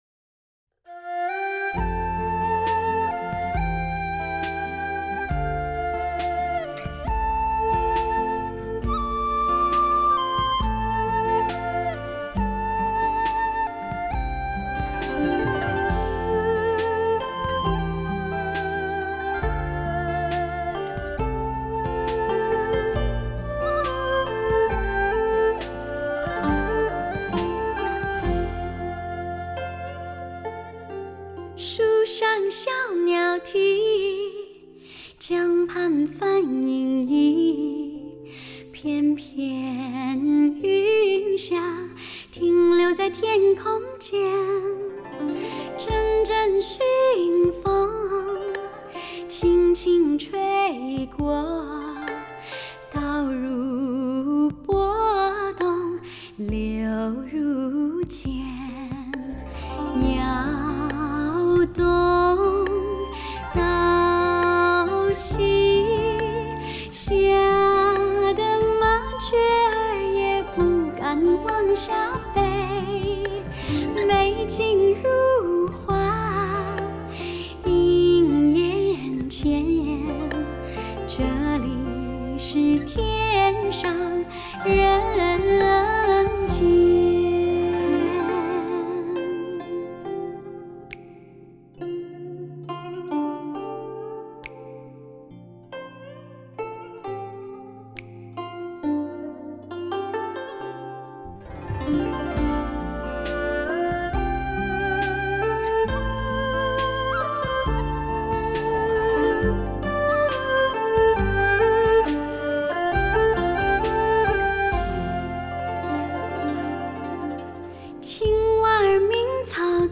发烧极品女声重温往日好时光